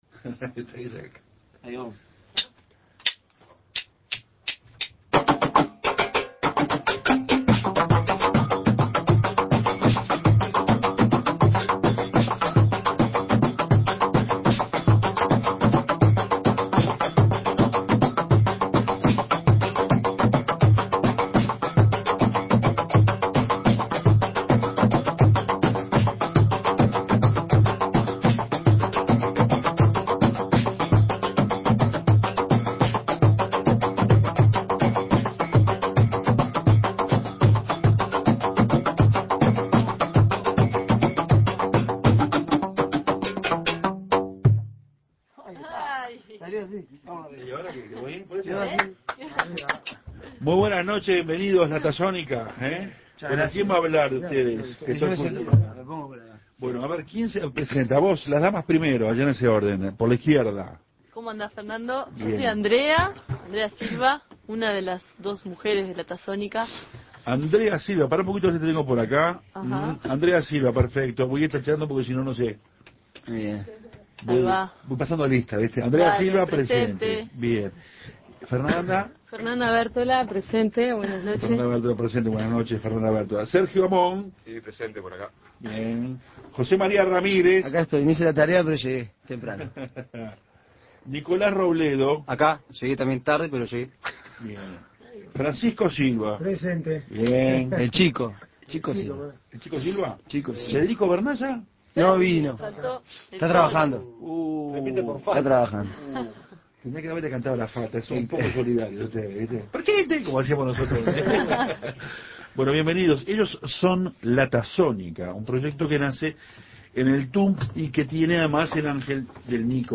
conjunto de percusión uruguayo